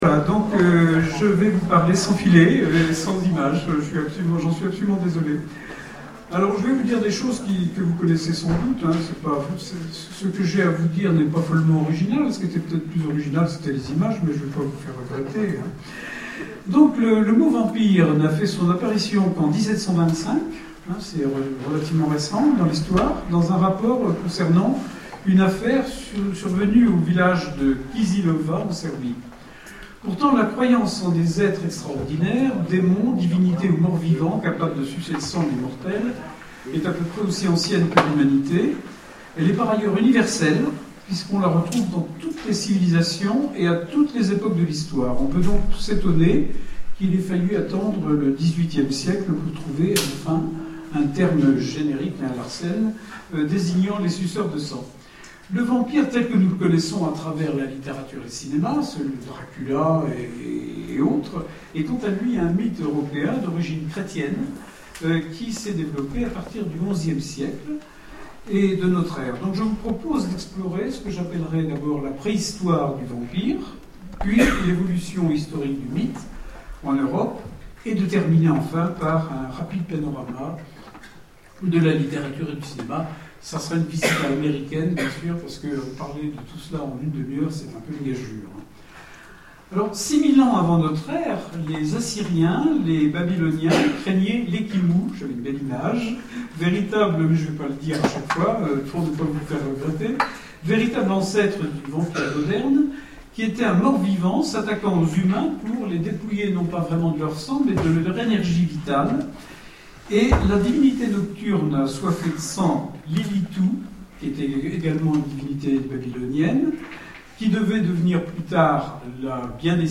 Etonnants Voyageurs 2012 : Conférence sur l'Histoire du Vampire